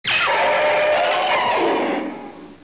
firerodan.wav